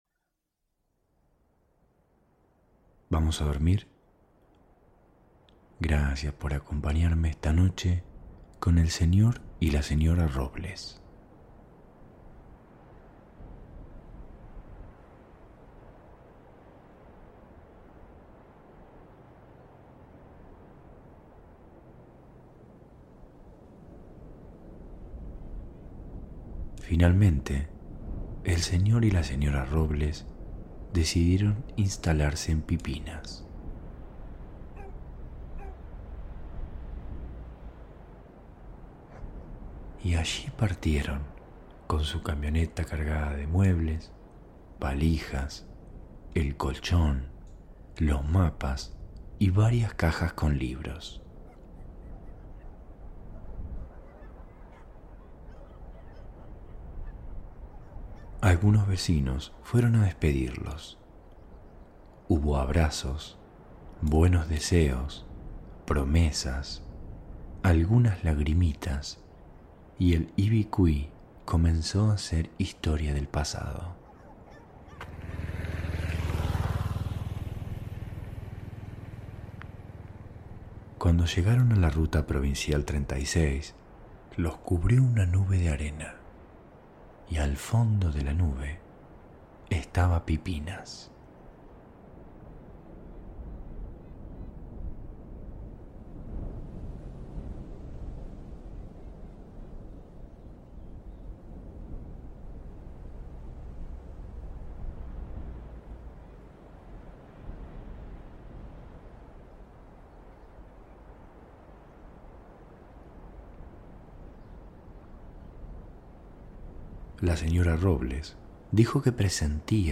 Cuentos ASMR para dormir - El señor y la señora Robles